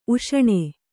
♪ uṣaṇe